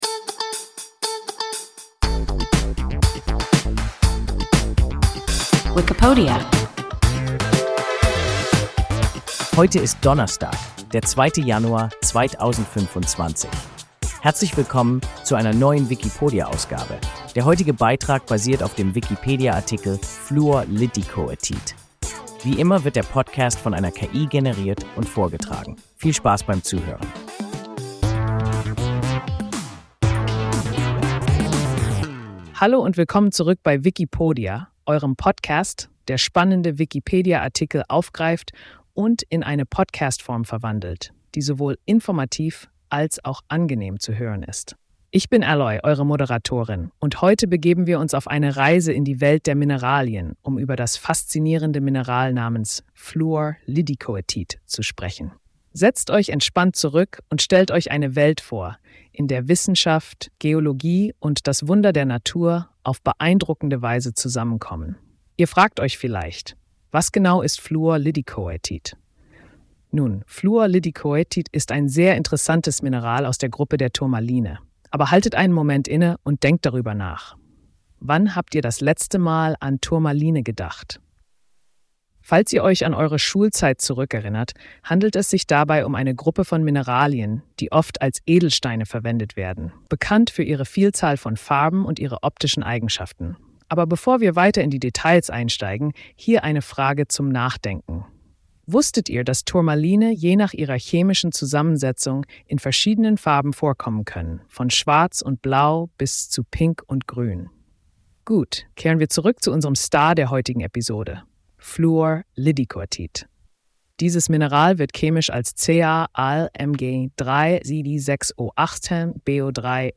Fluor-Liddicoatit – WIKIPODIA – ein KI Podcast